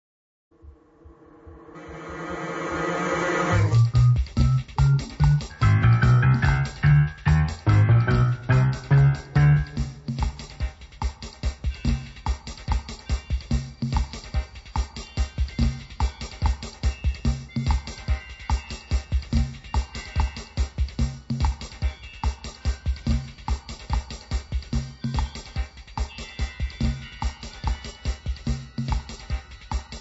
• registrazione sonora di musica